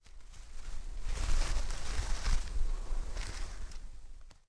脚步走在丛林2zth070524.wav
通用动作/01人物/01移动状态/06落叶地面/脚步走在丛林2zth070524.wav
• 声道 單聲道 (1ch)